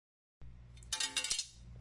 Звуки линейки
Пластмассовая линейка упала на стол